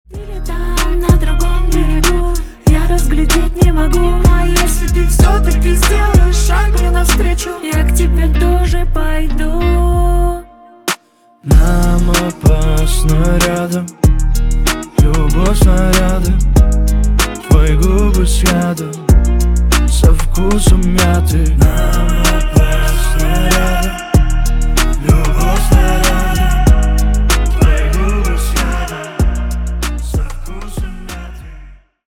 на русском про любовь грустные